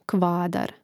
kvȃdar kvadar